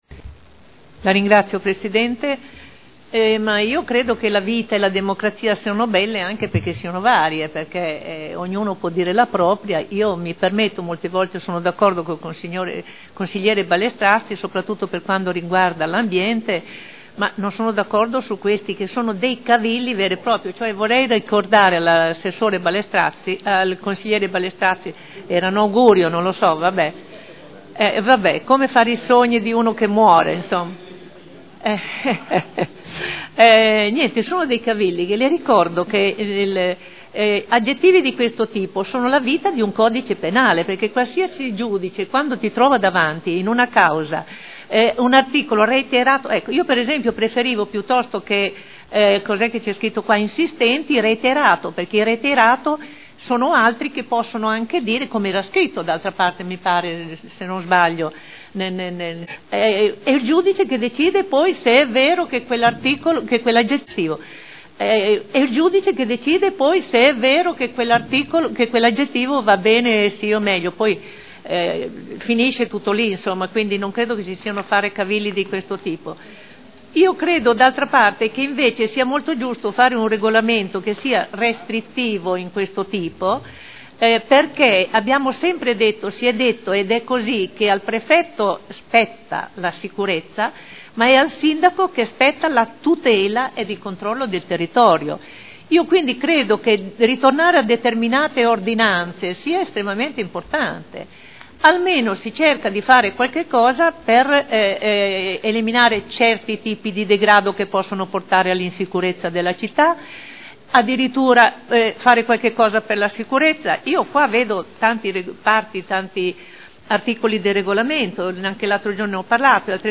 Olga Vecchi — Sito Audio Consiglio Comunale
Seduta del 20/06/2011. Modifiche al Regolamento di Polizia Urbana approvato con deliberazione del Consiglio comunale n. 13 dell’11.2.2002 Dibattito